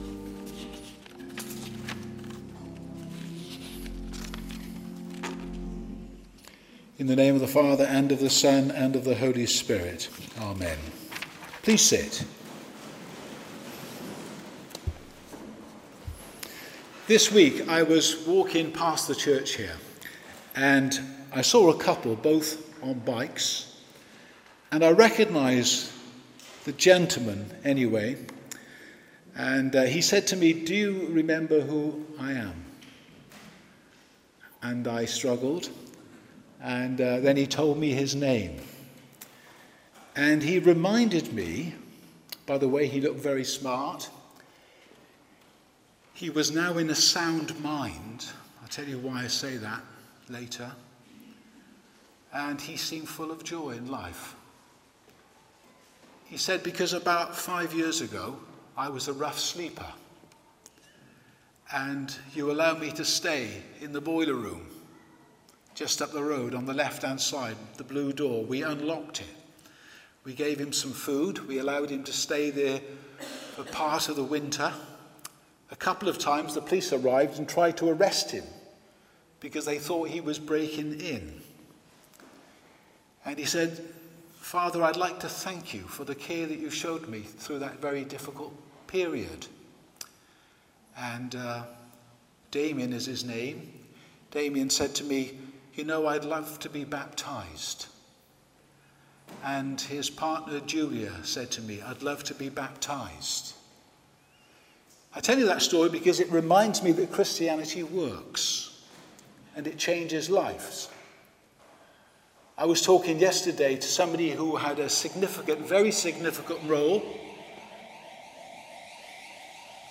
Sermons - St Andrews Church